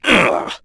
Chase-Vox_Damage_02.wav